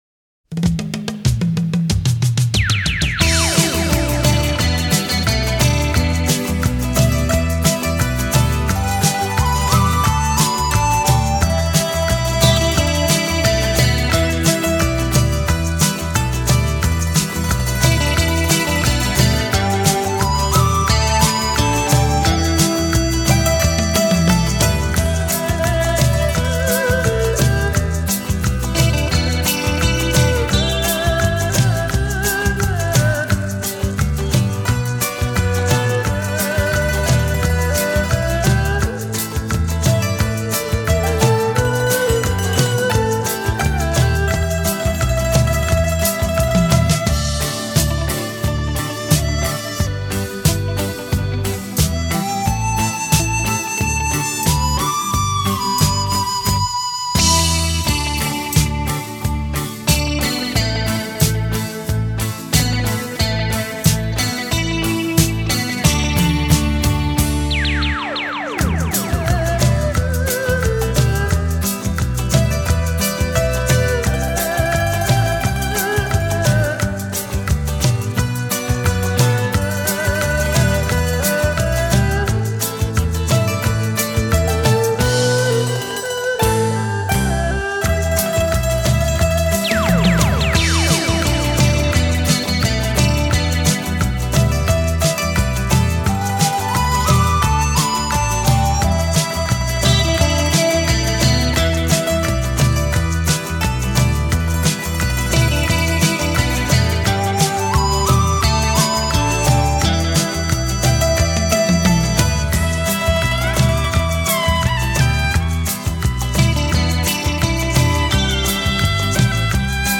古箏金曲滿天星 特殊演奏傳情意
繞場立體声音效 發燒音樂重绕梁